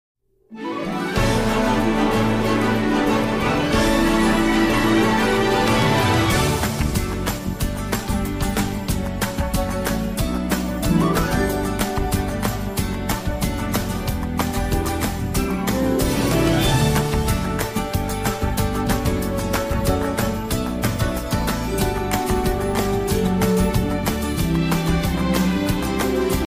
[Instrumental]